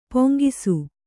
♪ poŋgisu